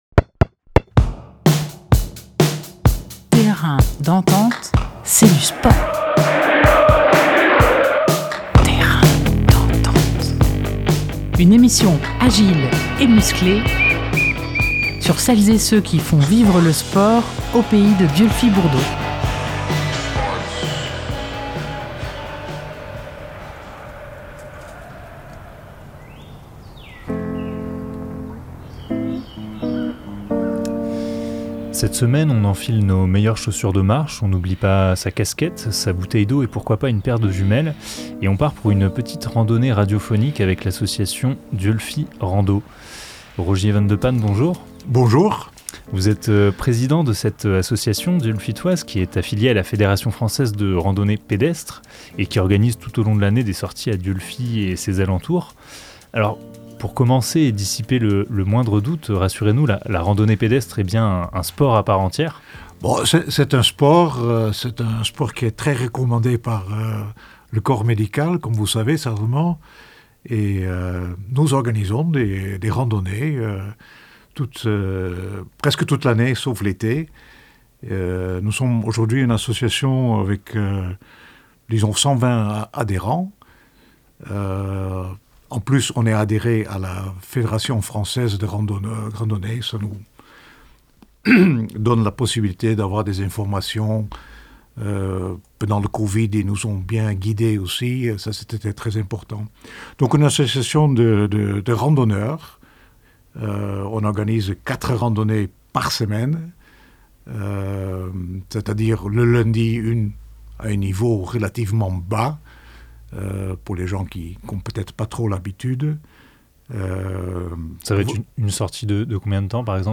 22 février 2023 14:52 | actus locales, Interview, Terrain d'entente